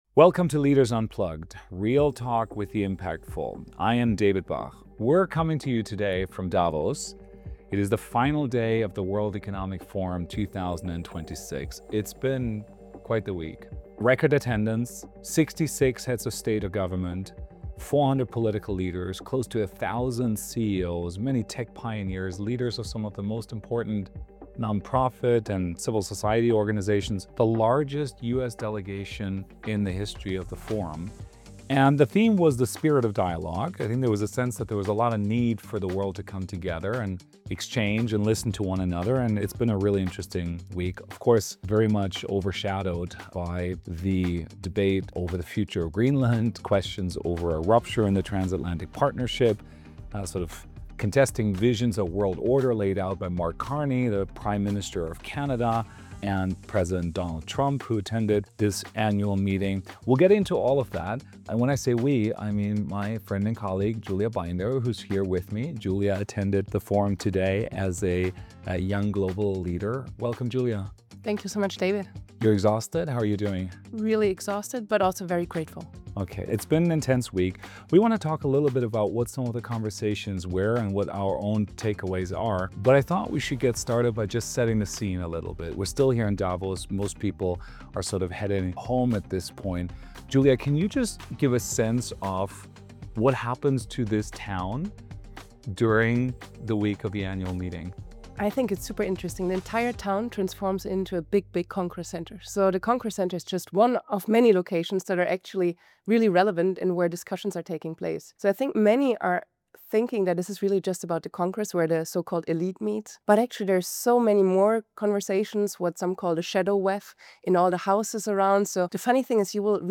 Leaders-Unplugged_Podcast_Reflections-from-Davos.mp3